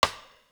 [PBS] Rimshot 1.wav